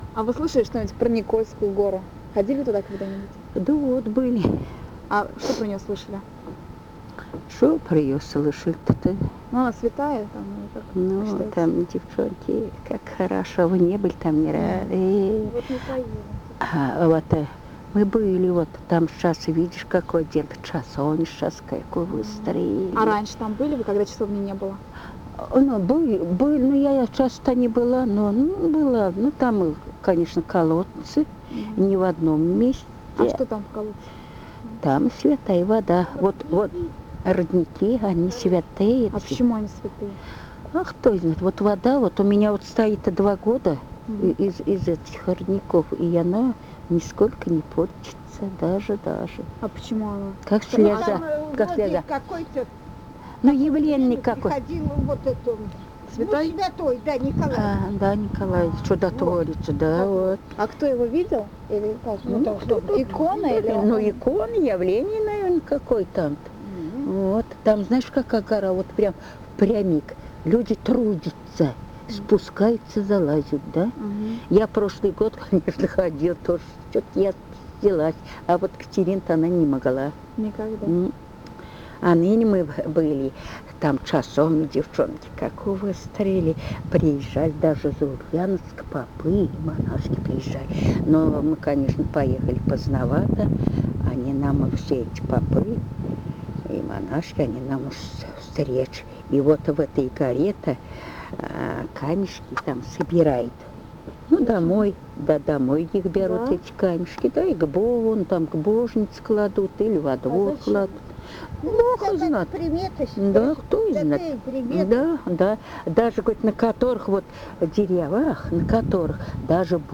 Рассказ